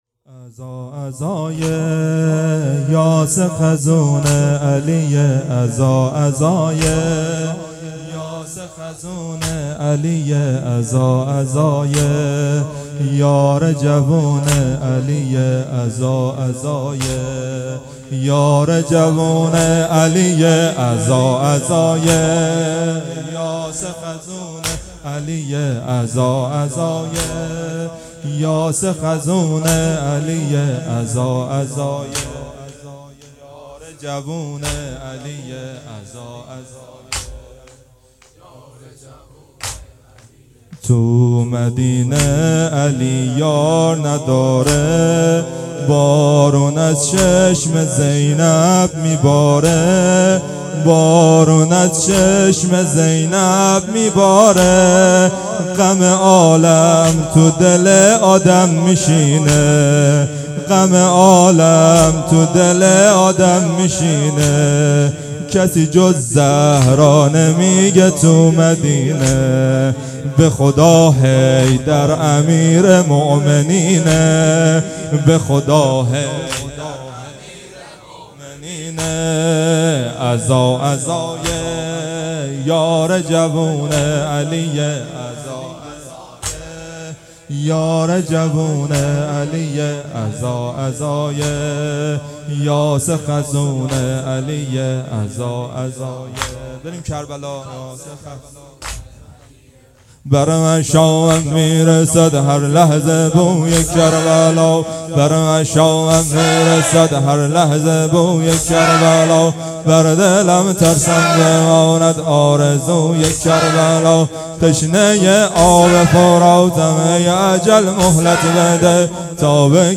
عزا عزای یاس خزون علیه _ واحد
اقامه عزای شهادت حضرت زهرا سلام الله علیها _ دهه دوم فاطمیه _ شب اول